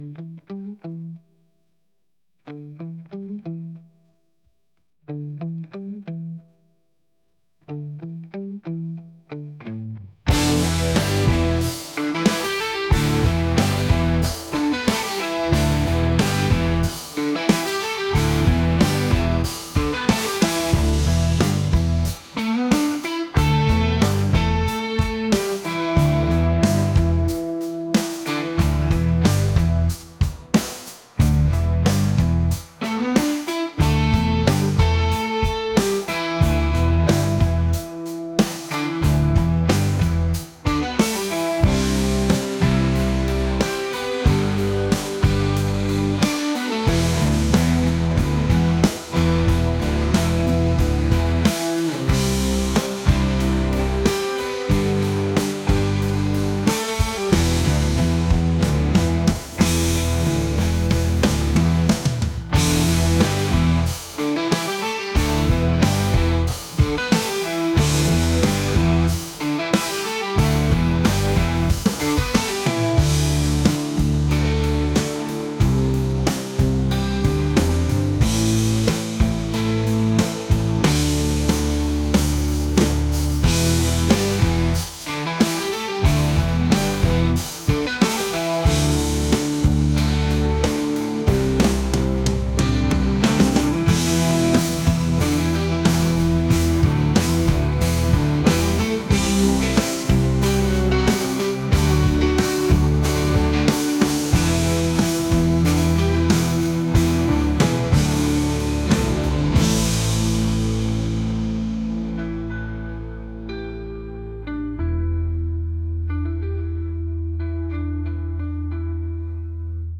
rock | acoustic | soul & rnb